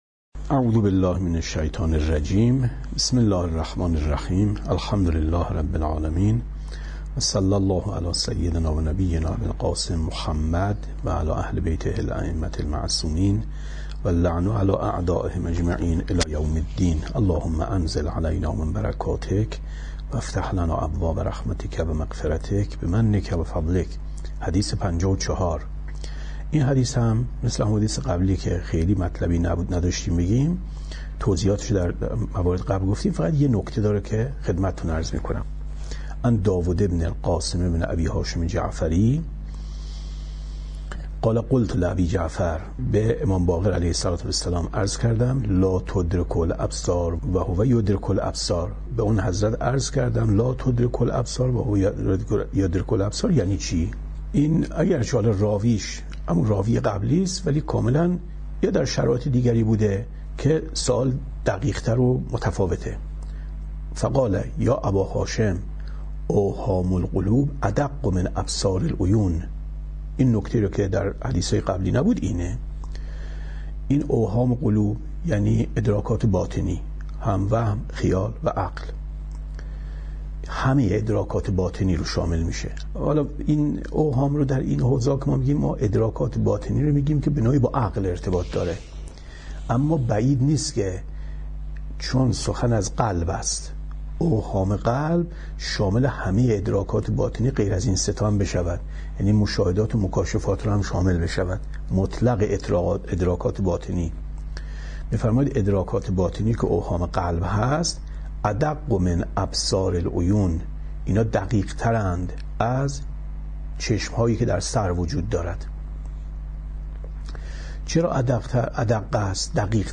کتاب توحید ـ درس 54 ـ 25/ 10/ 95